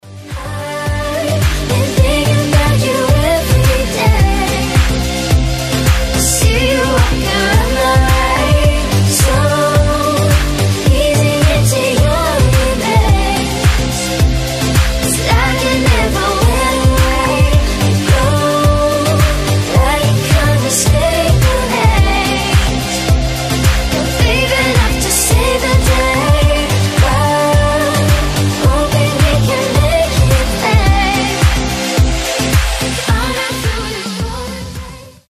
танцевальные , deep house
женский голос